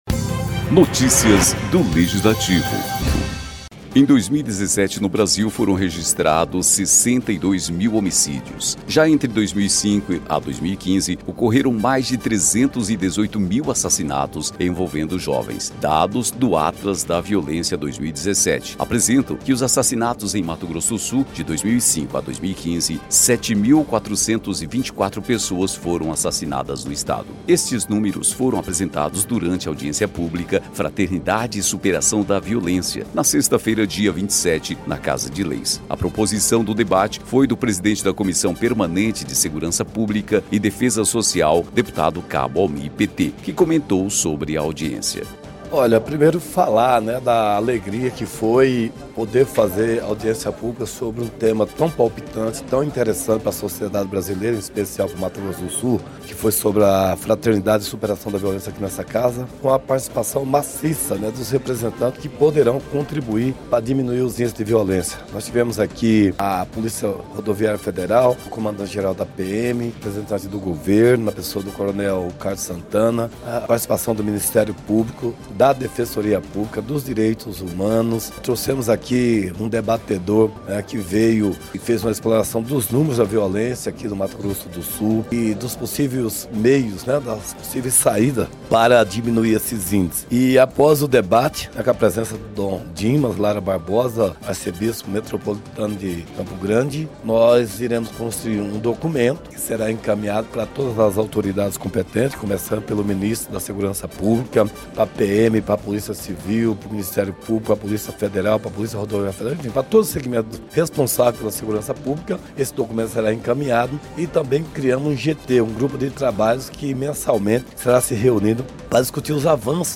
Números da violência apresentados durante audiência pública preocupam autoridades